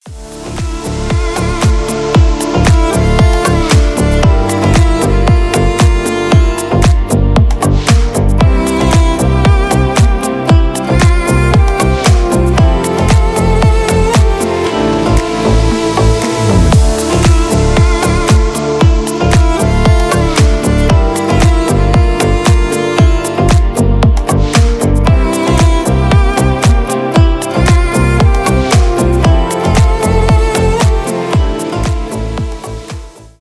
deep house , танцевальные
без слов , скрипка , инструментальные